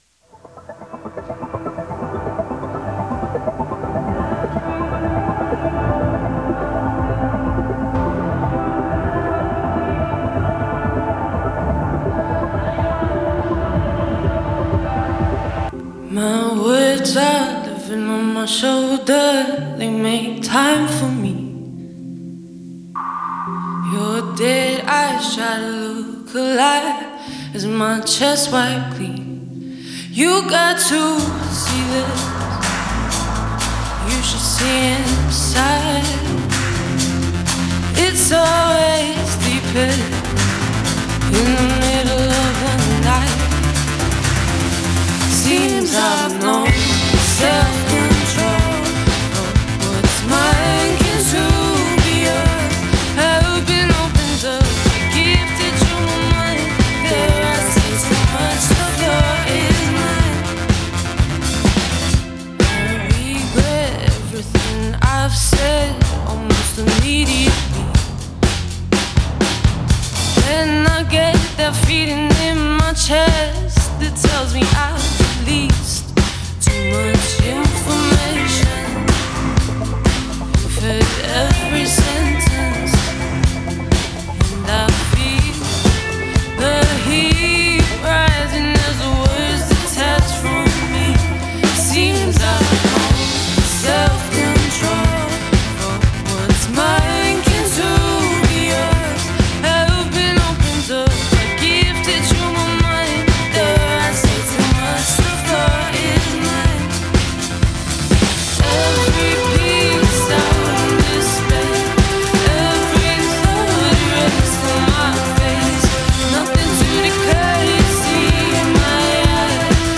new wave-pop duo
vocals
guitar